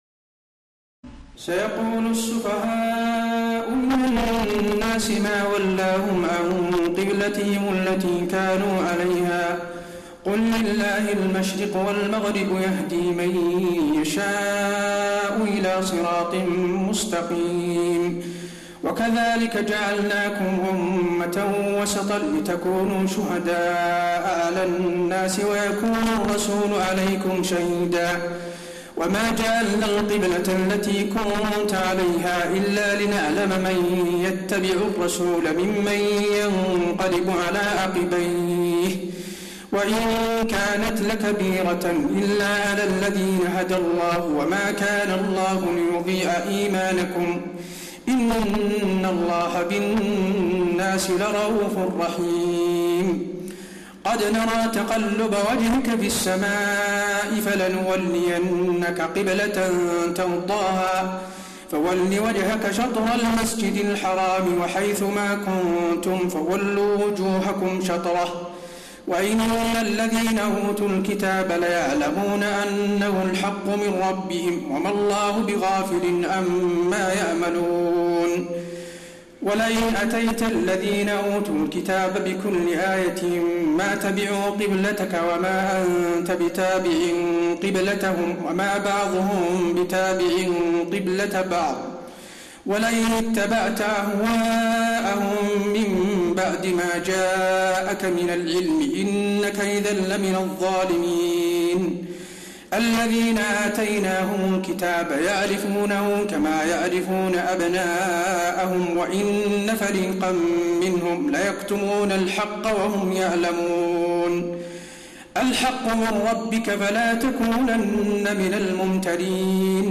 تراويح الليلة الثانية رمضان 1432هـ من سورة البقرة (142-203) Taraweeh 2 st night Ramadan 1432H from Surah Al-Baqara > تراويح الحرم النبوي عام 1432 🕌 > التراويح - تلاوات الحرمين